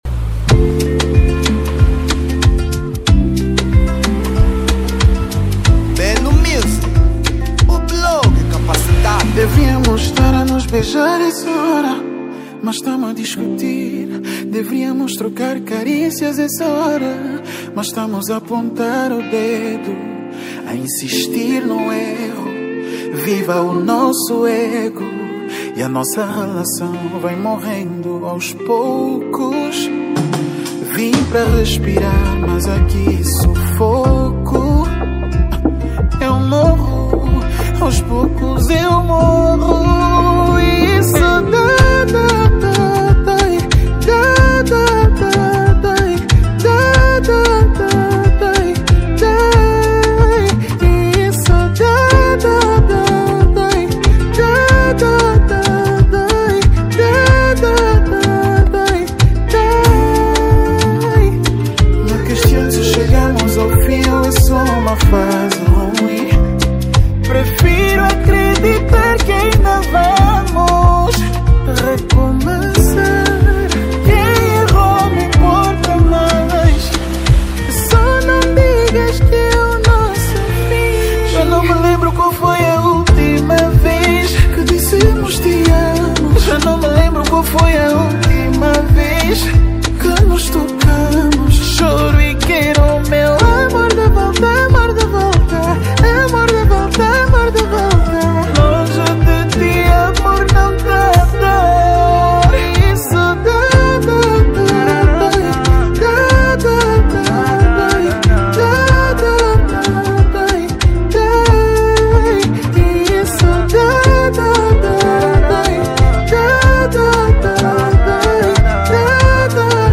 Género : Zouk